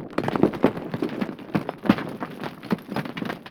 rockFall.wav